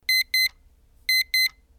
alarm2
アラーム音２回×２
alarm2.wav